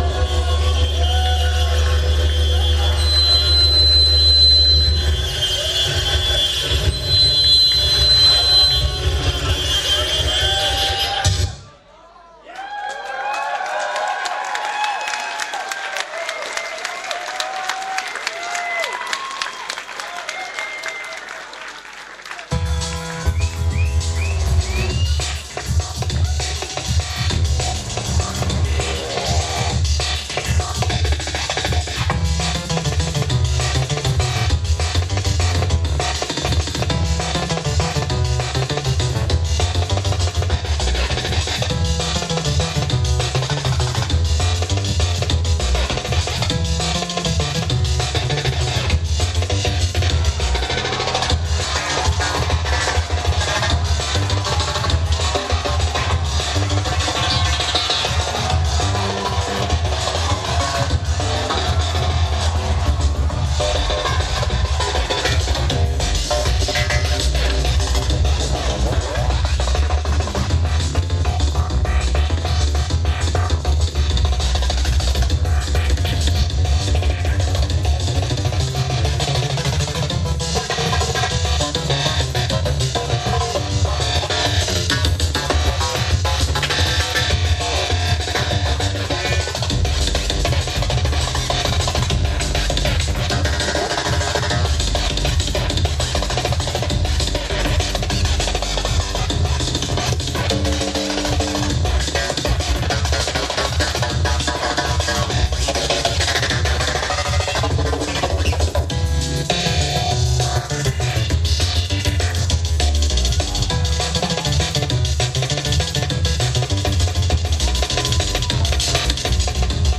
venue Belongil Fields (Byron Bay)